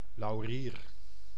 Ääntäminen
IPA : /ˈlɒɹ.əl/